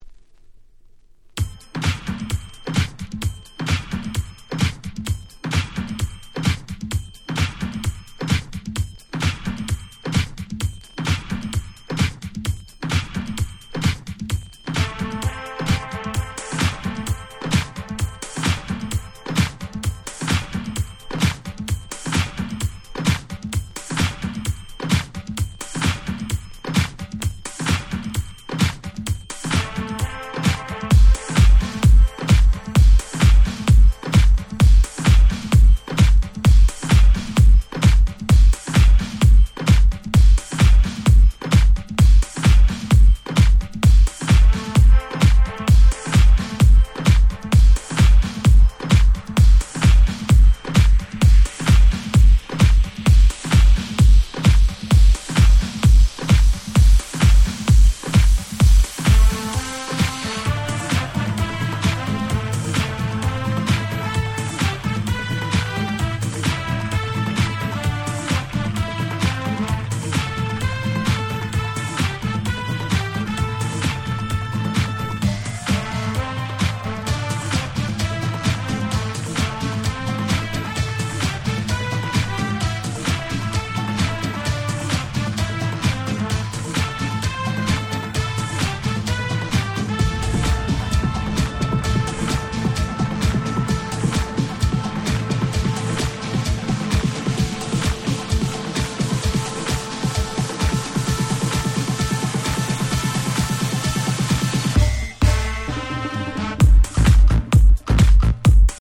夏ソング アゲアゲ EDM レゲエ Reggae 00's R&B